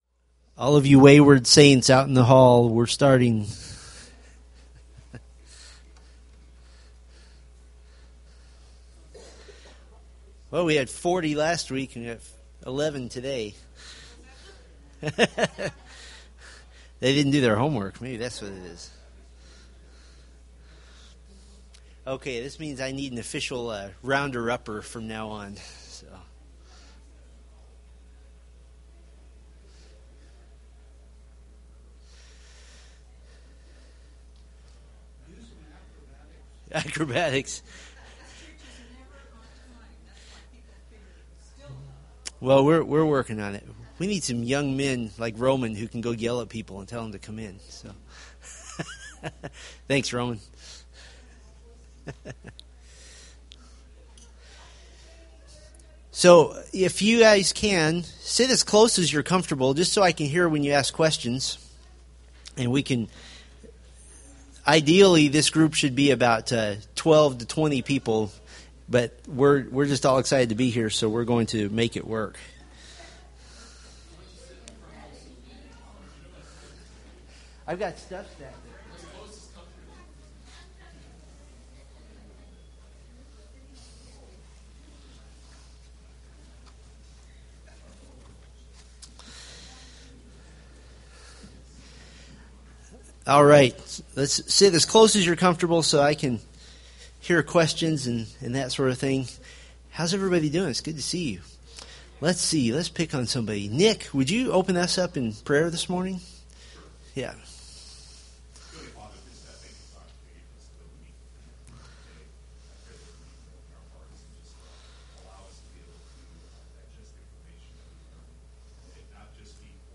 Date: Apr 14, 2013 Series: Fundamentals of the Faith Grouping: Sunday School (Adult) More: Download MP3